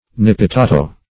\Nip`pi*ta"to\